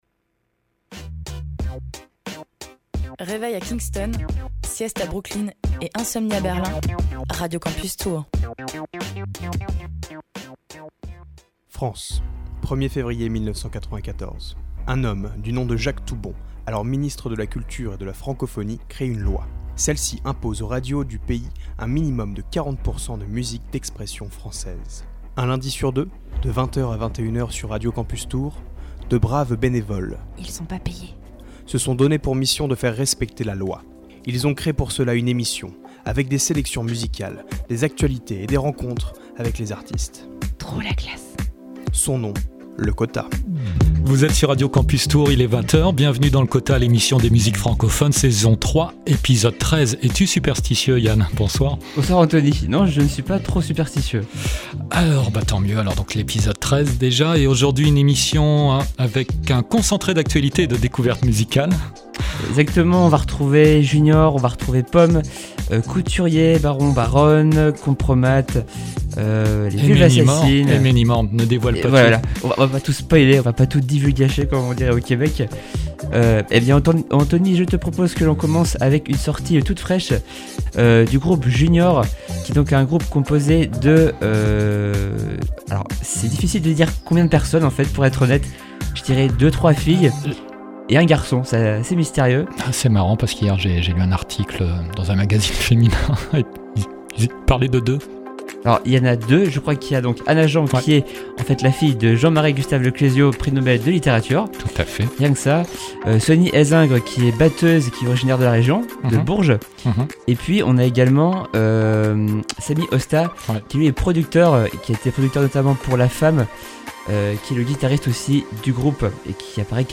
Le Quota, c’est le magazine des musiques francophones de Radio Campus Tours un lundi sur deux de 20h à 21h et en rediffusion le vendredi à 8h45.